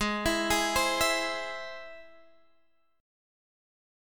Listen to G#+ strummed